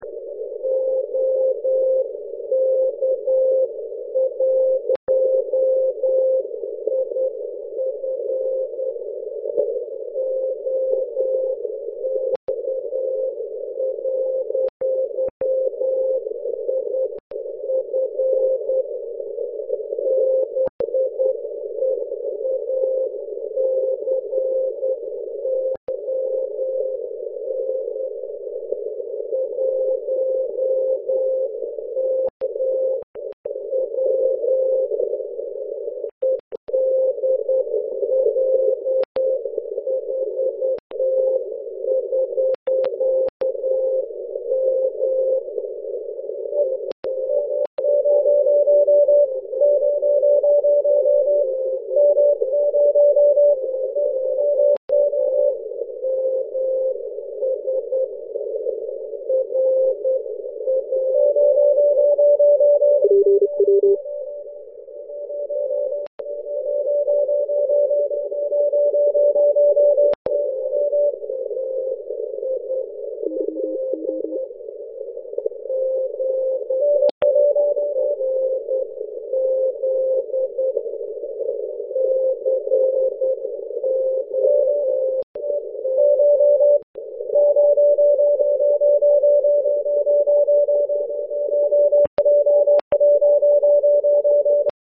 V nahr�vce jsou v�padky - lupance způsoben� poč�tačem. V z�věru nahr�vky se snaž� někdo maj�k rušit. Kritick� kmitočet byl 7.6MHz Rig: TS-480SAT +Xtal filtr 300Hz LW41m v průměrn� v�šce cca 17m (7m šikmo z okna nahoru do 20m, pak šikmo dolů na strom cca 5m) na multiband vertical ECO (24m nad zem�) byl poslech slabš� a zarušenějš�.